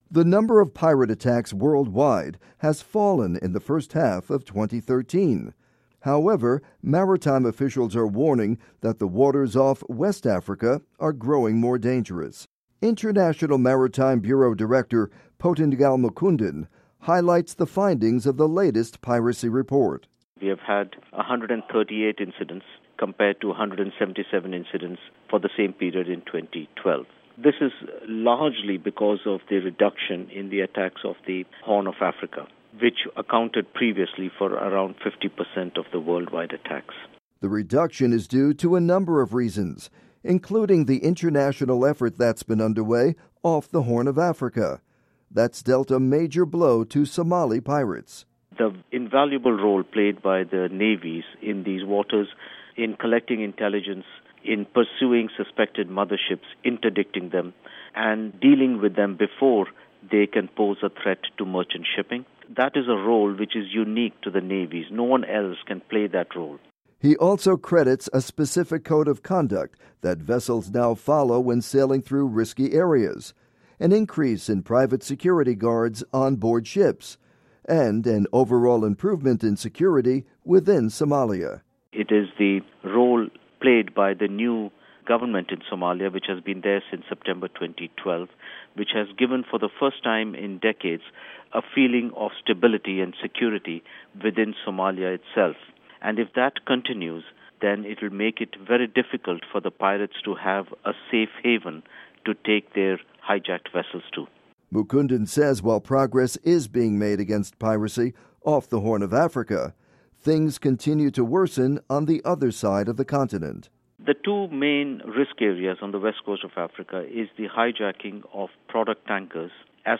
report on piracy